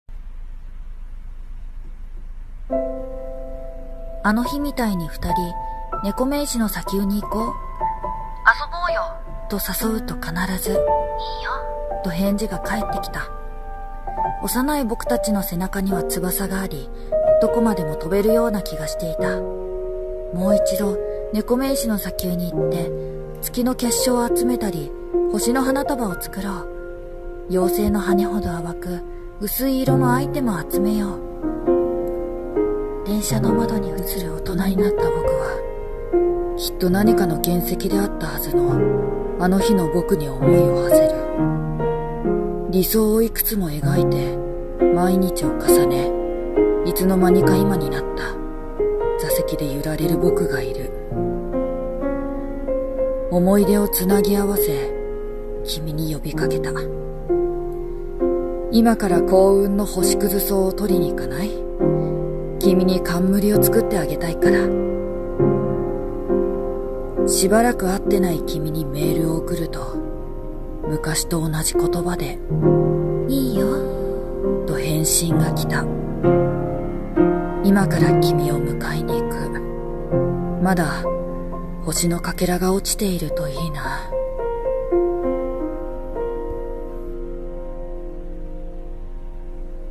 声劇 追憶